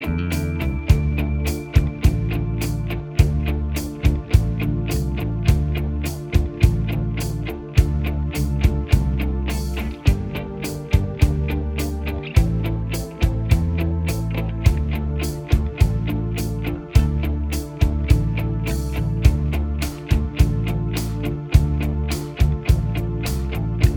Minus Guitars Except Rhythm Rock 4:47 Buy £1.50